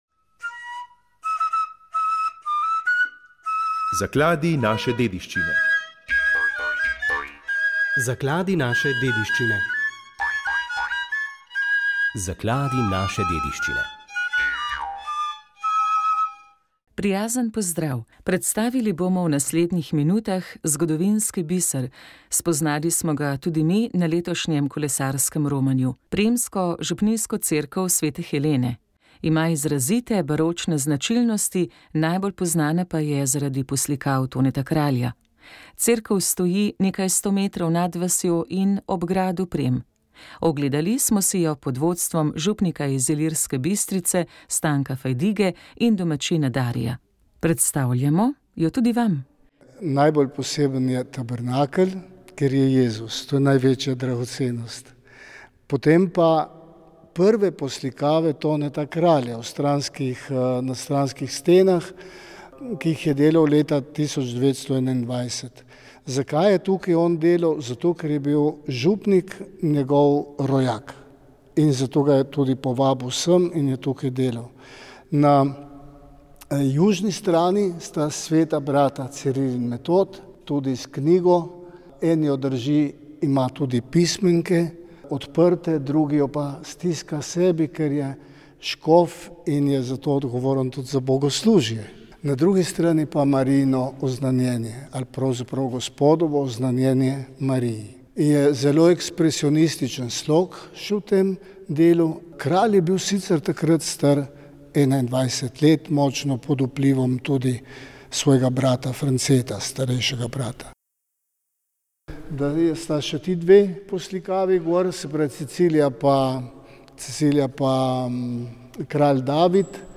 Komentar